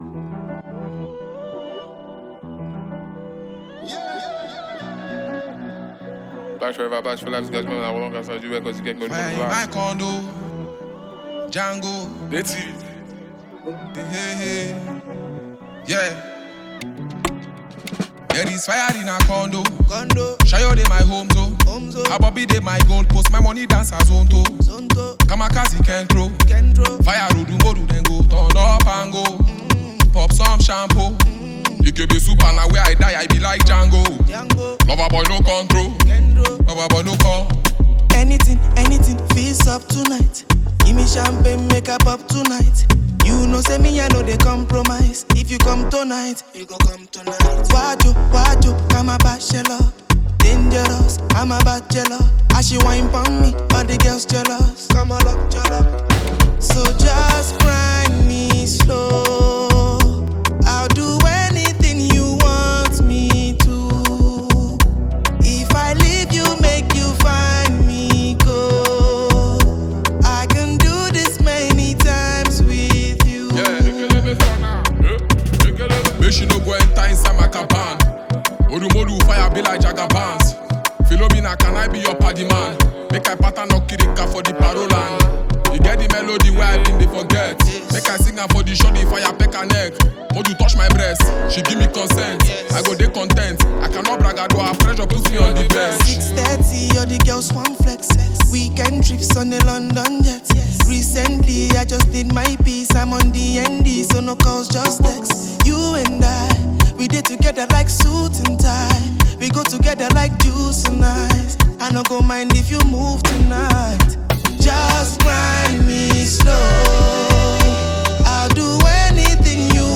In the potential chart-topping uptempo record
go head-to-head with wonderful melodies and flow.